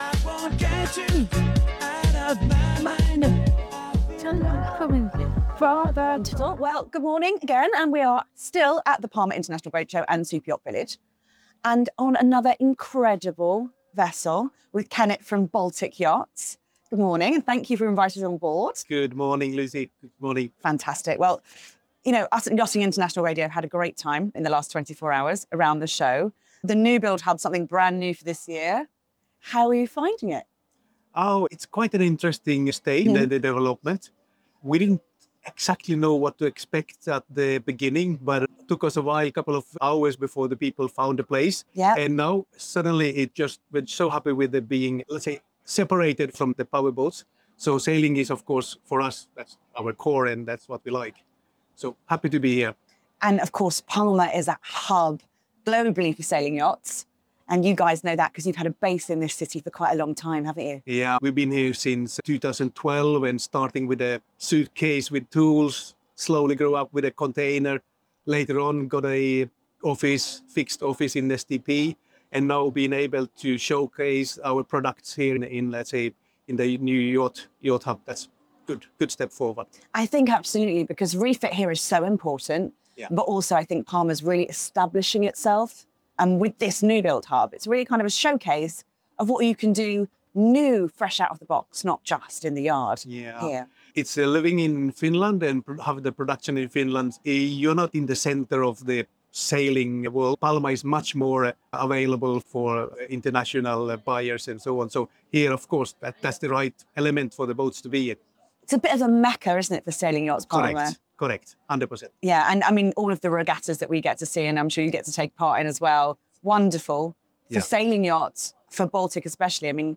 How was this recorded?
📍 Recorded at the Palma Superyacht Village 2025